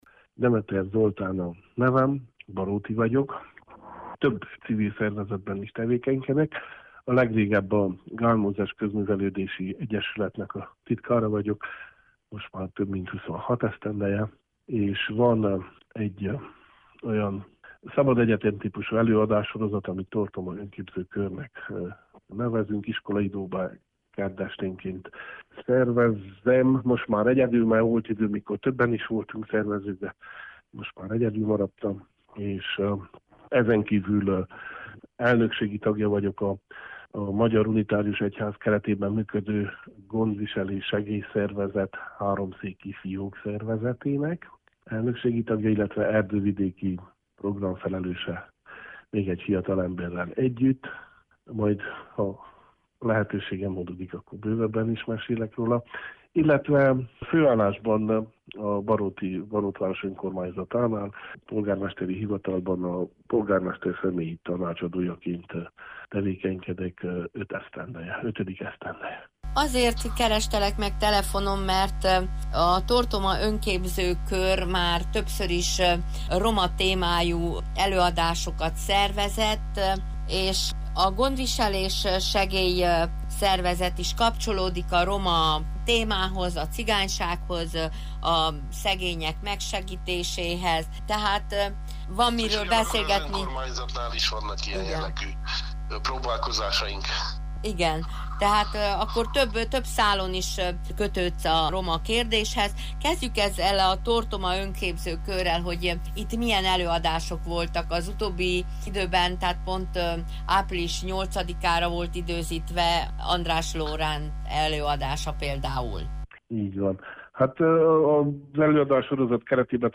telefonbeszélgetés